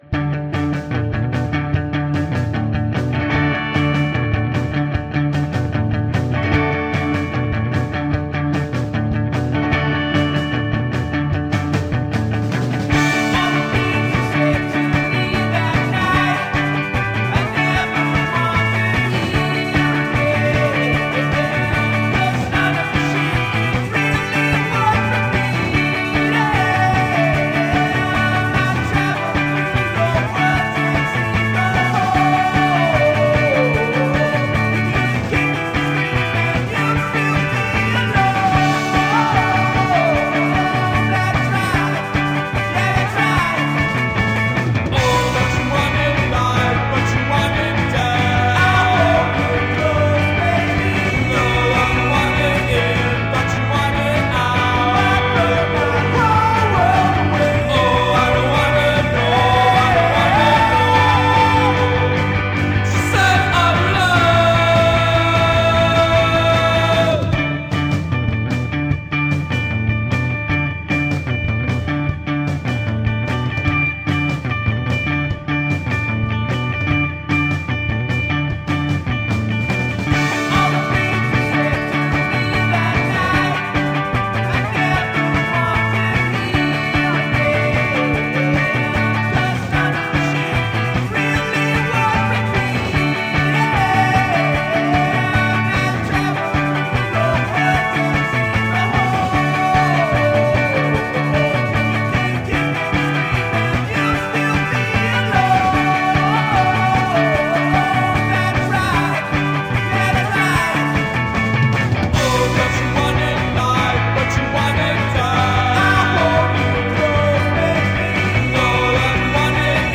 pop-flaovoured garage rock